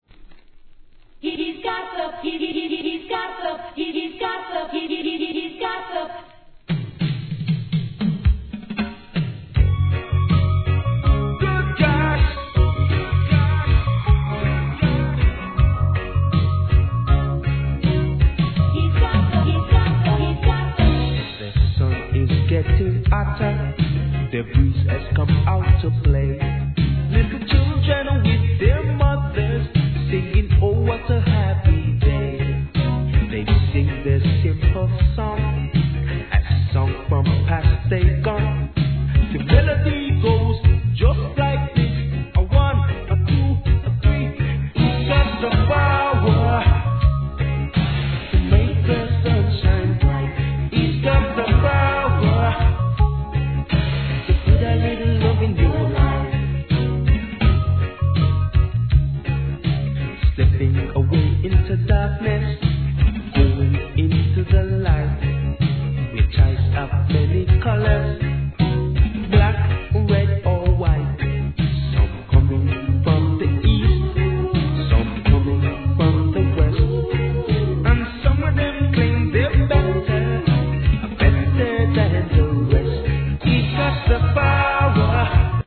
REGGAE
コーラスもいい感じです。